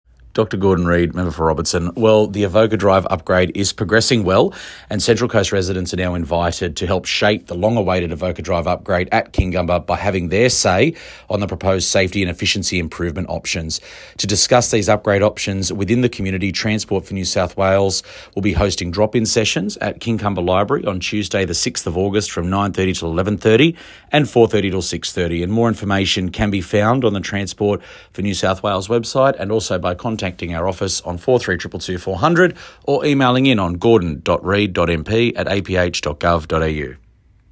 Dr Gordon Reid MP beside Avoca Drive, Kincumber - (Click Image to listen to sound clip).